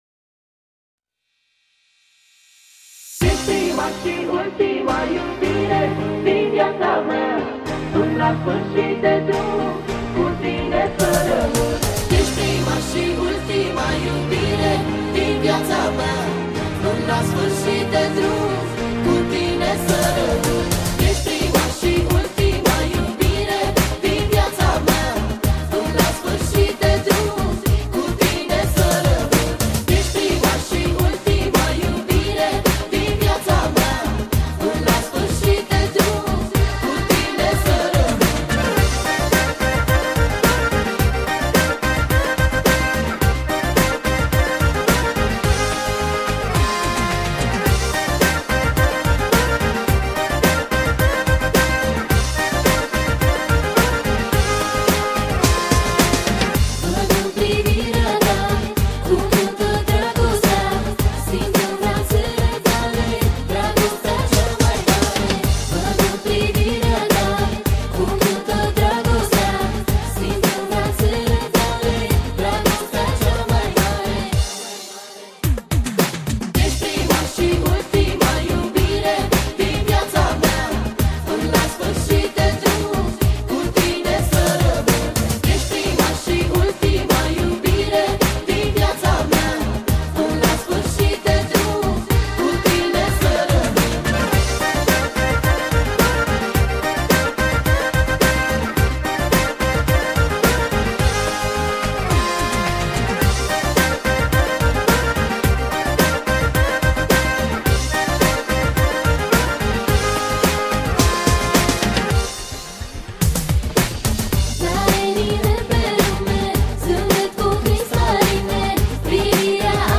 Data: 10.10.2024  Manele New-Live Hits: 0